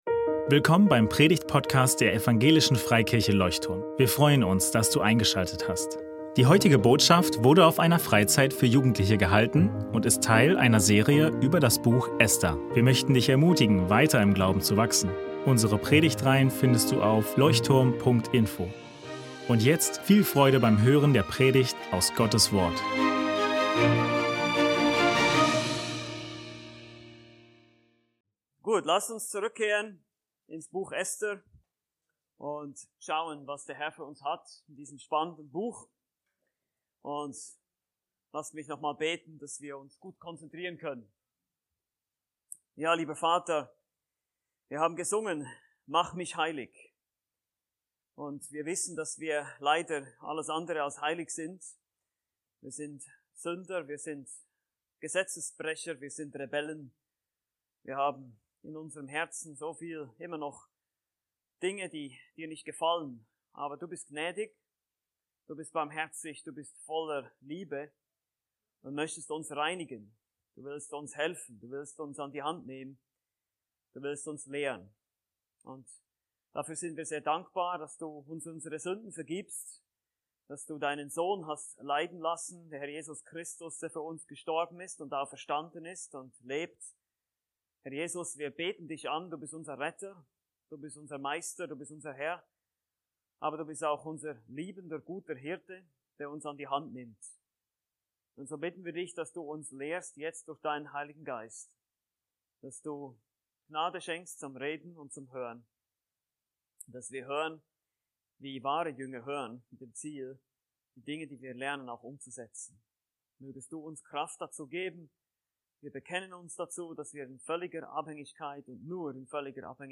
Gewagter Einsatz ~ Leuchtturm Predigtpodcast Podcast